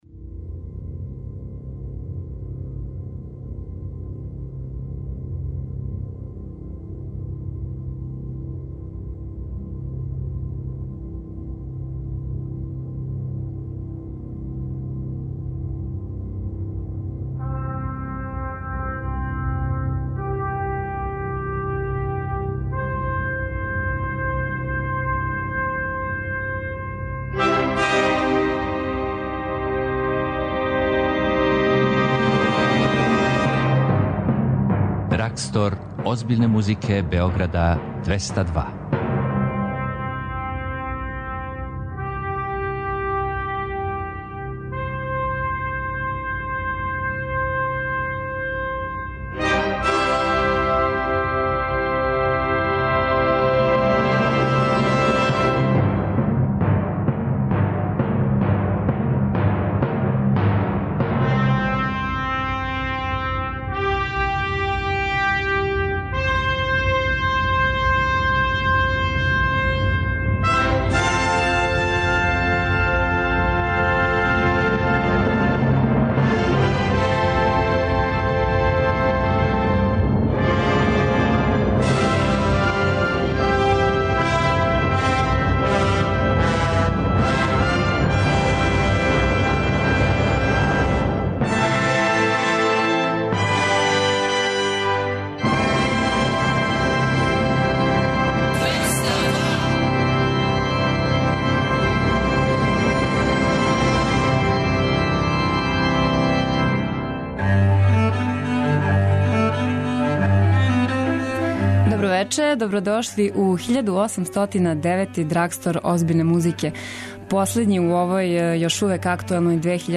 Заокружујемо 2015. годину уз најлепша класична дела, најпознатије композиторе и најчувеније извођаче свих жанрова!